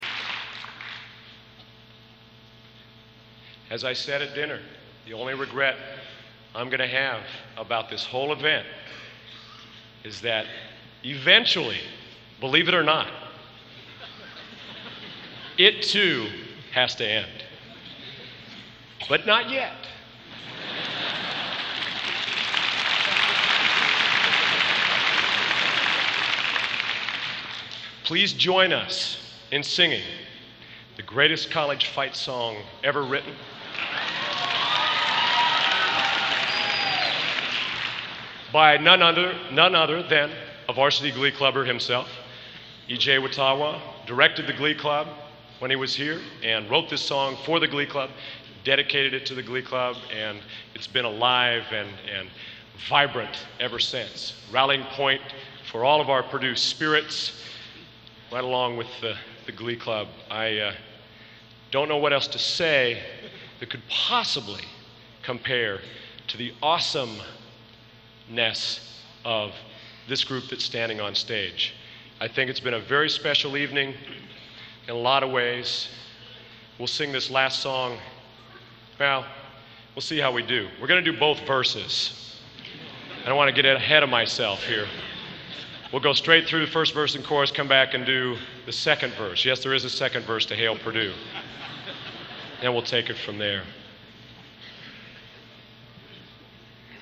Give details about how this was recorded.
Collection: Centennial Celebration Concert 1993